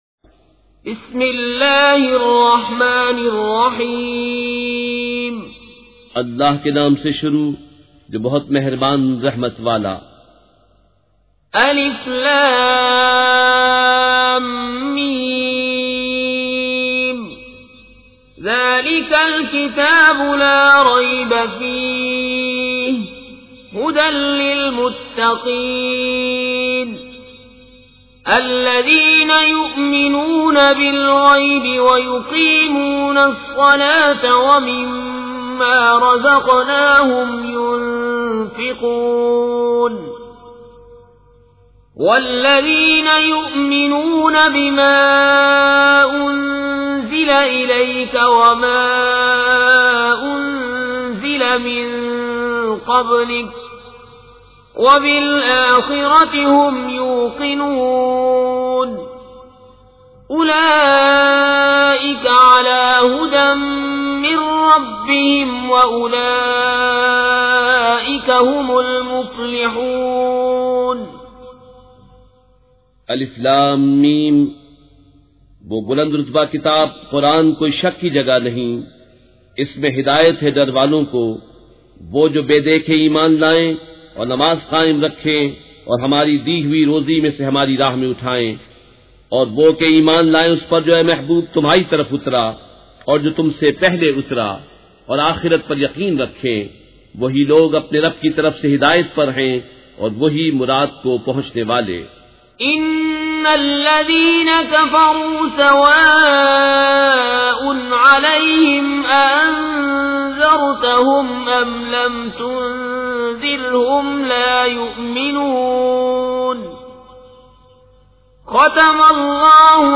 سورۃ البقرہ مع ترجمہ کنزالایمان ZiaeTaiba Audio میڈیا کی معلومات نام سورۃ البقرہ مع ترجمہ کنزالایمان موضوع تلاوت آواز دیگر زبان عربی کل نتائج 4747 قسم آڈیو ڈاؤن لوڈ MP 3 ڈاؤن لوڈ MP 4 متعلقہ تجویزوآراء